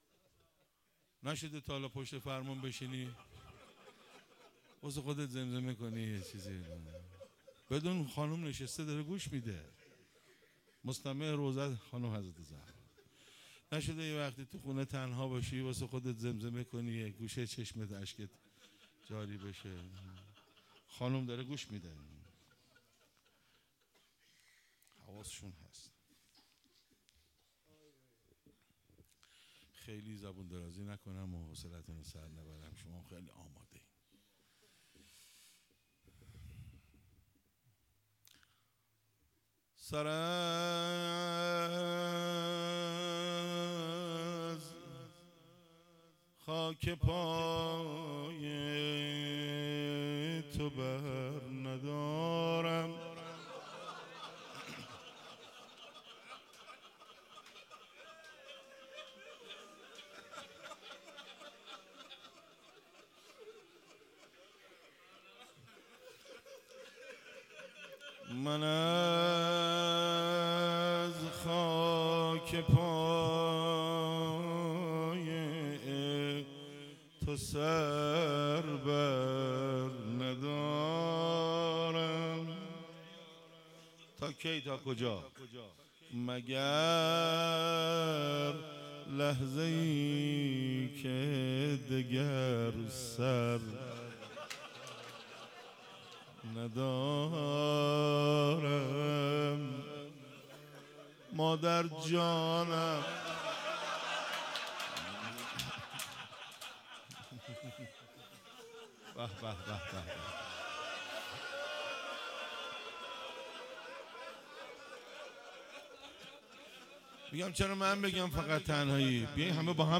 فاطمیه 96 - روضه - سر از خاک پای تو بر ندارم